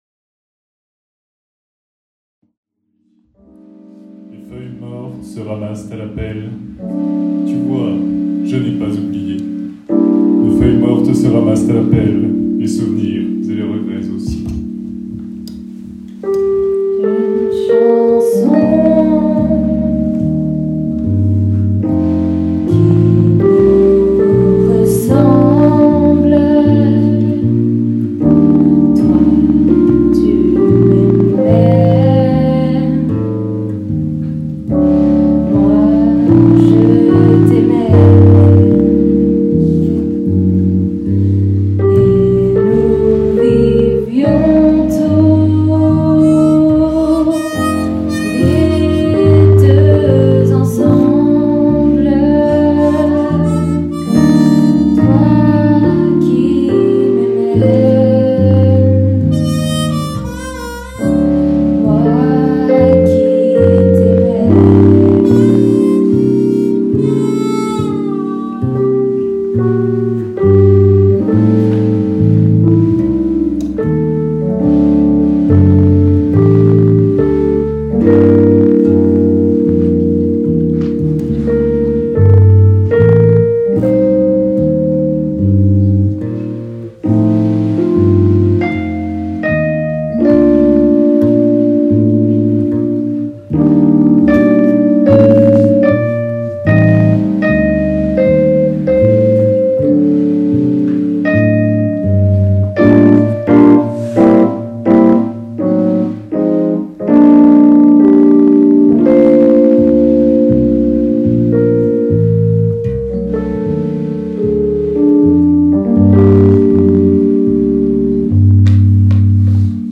Pour cette ultime représentation ils avaient choisi d'interpréter leur propre arrangement de la chanson "Les feuilles mortes", produit en un temps record.
C'est une des chansons françaises les plus enregistrées dans le monde, un standard de jazz planétaire.